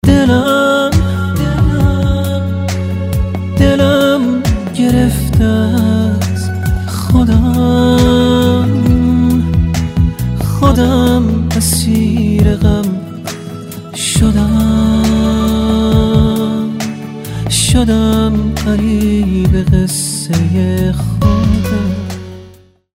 رینگتون نرم و باکلام